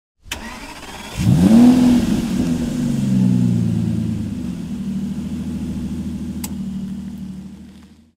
PLAY Auto Hupe
auto-hupe.mp3